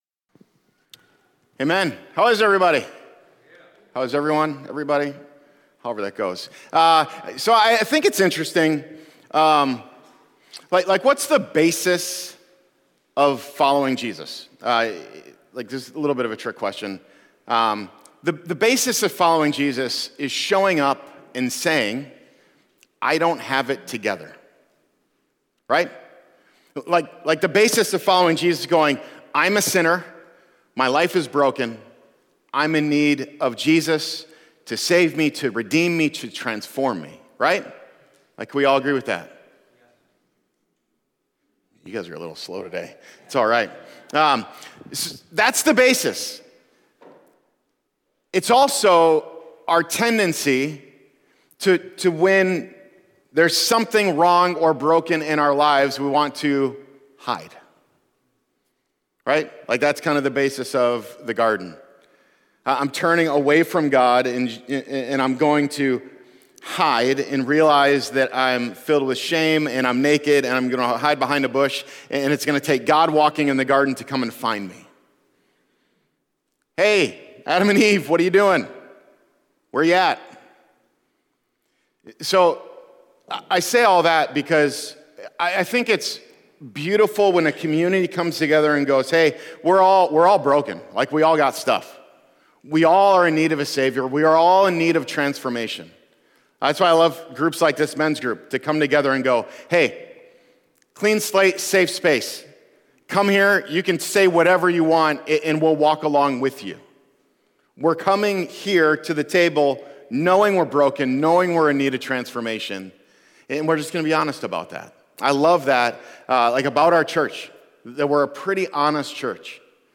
1 Dollar in kritischer Phase: Kommt das Währungssystem ins Rutschen? / Interview